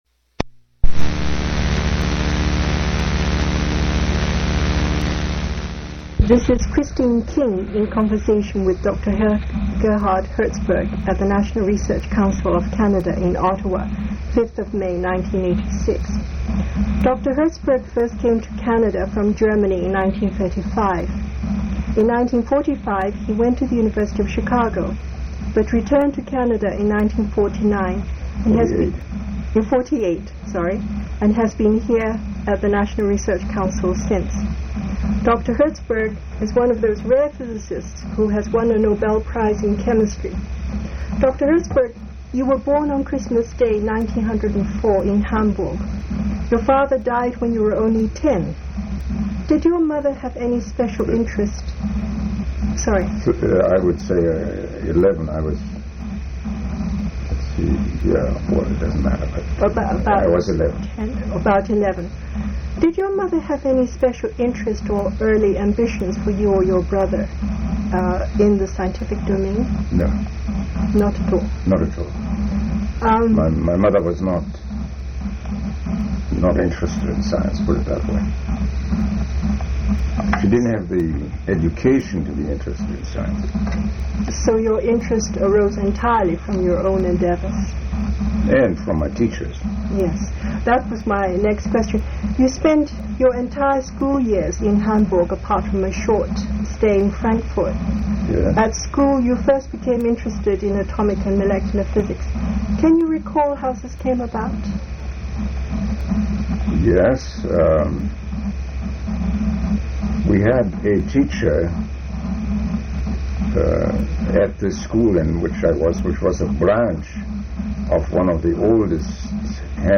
Oral history interview with Gerhard Herzberg
Place of interview National Research Council of Canada